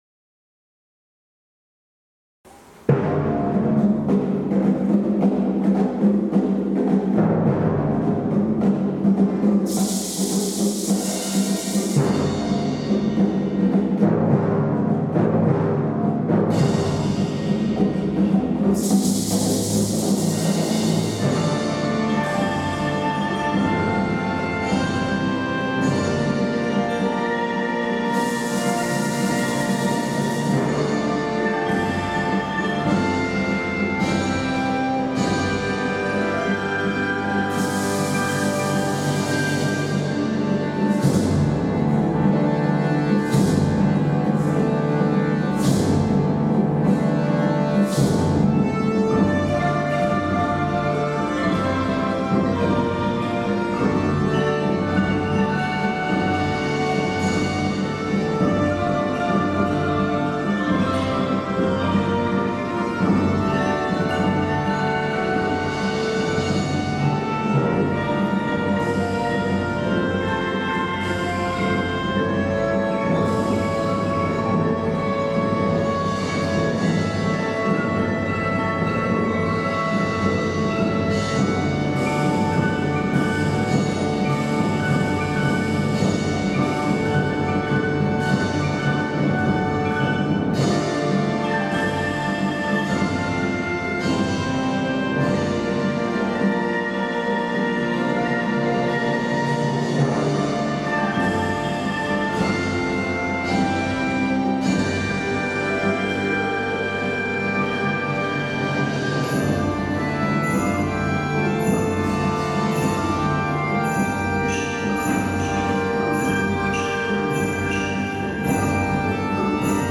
難しいリズムに苦戦しながらも、まわりの音に自分の音をそそぎこむということを意識しました。サバンナを連想させるような壮大な音楽は大迫力でした♪